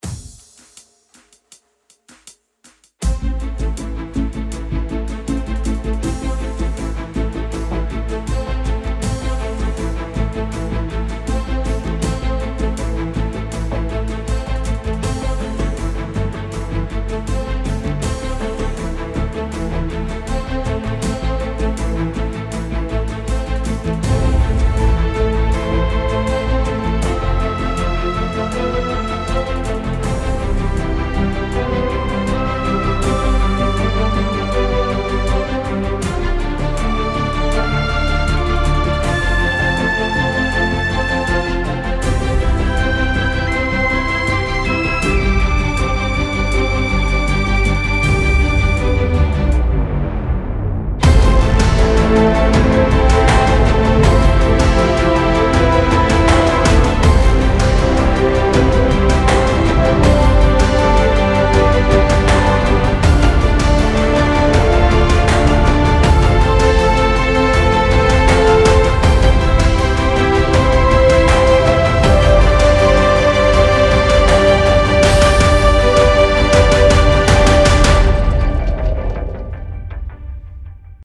Epic Thema braucht mehr Spielzeit